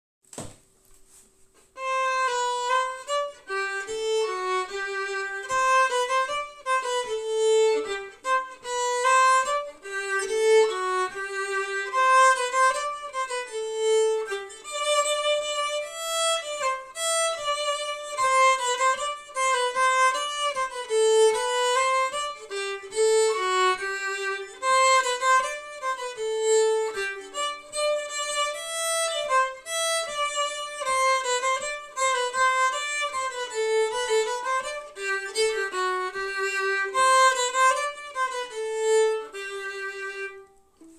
An archive of fiddle and dance tunes, both traditional and new, from Scandinavia, England, Ireland, Scotland, Cape Breton, New Brunswick, Quebec, New England, Appalachia and more, for traditional musicians.
Key : G* Form : March or air MP3